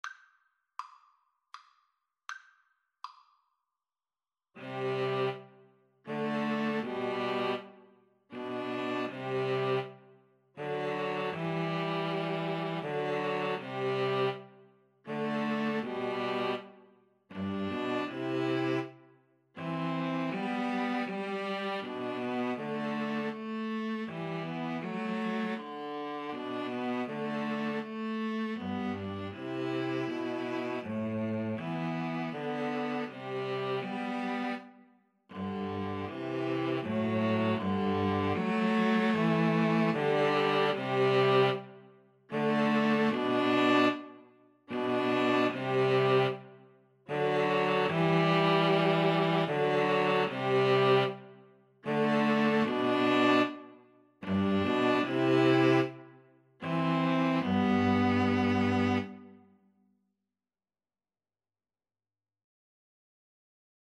Play (or use space bar on your keyboard) Pause Music Playalong - Player 1 Accompaniment Playalong - Player 3 Accompaniment reset tempo print settings full screen
G major (Sounding Pitch) (View more G major Music for String trio )
3/4 (View more 3/4 Music)
Andante
String trio  (View more Easy String trio Music)
Classical (View more Classical String trio Music)